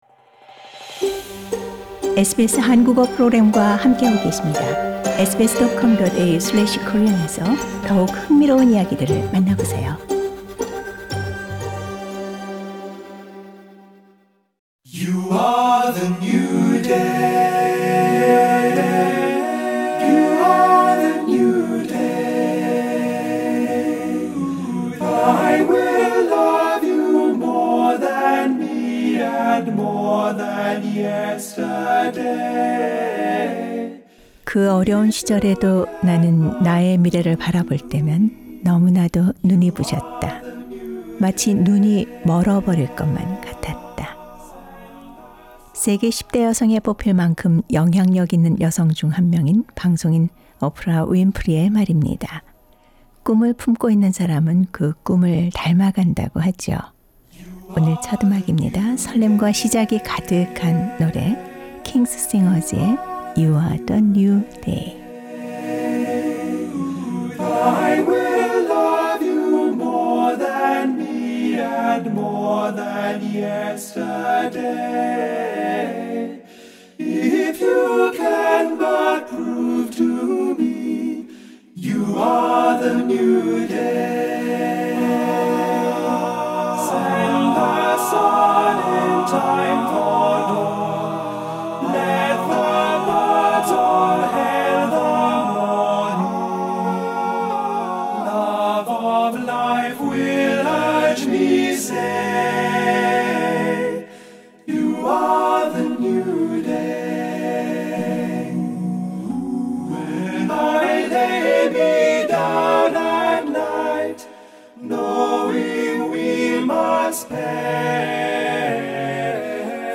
2020년에는 어떤 꿈을 이루게 될까요? 새해의 메시지를 담은 희망의 노래와 함께 새해를 설계해보세요!